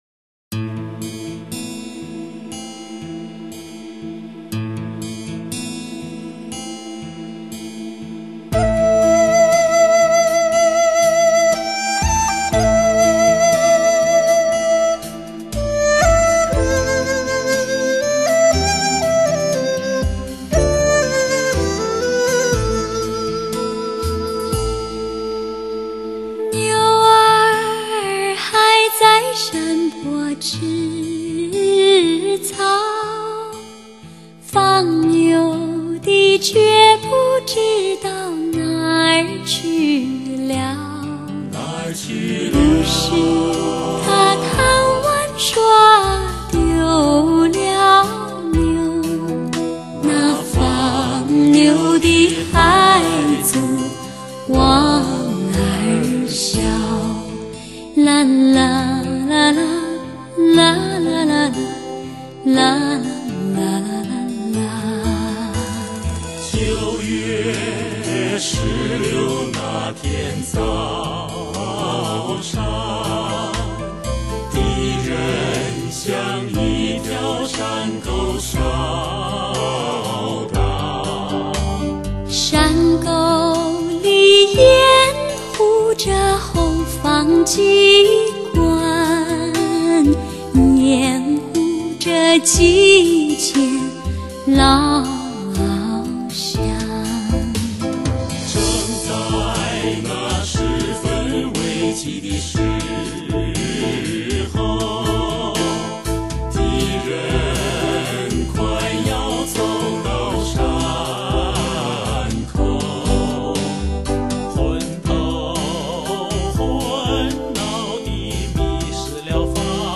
极品人声 绝佳发烧天籁
极品人声，绝佳发烧天籁，低吟浅唱，HI-FI试音精品,